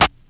Snare (Family Business).wav